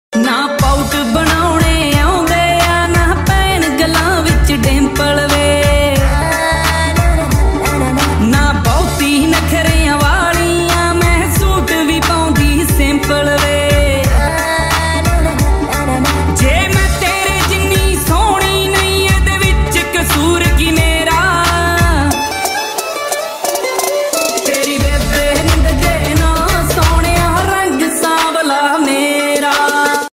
Ringtones Category: Punjabi